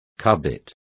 Complete with pronunciation of the translation of cubit.